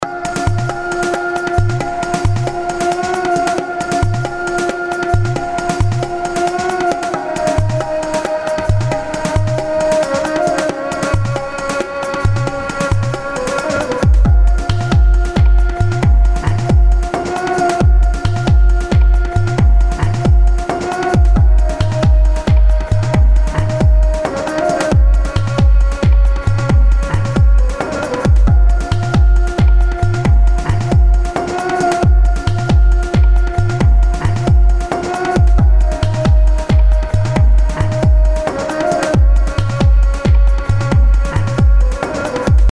Some dub from the steppe.
Sidechaining fever.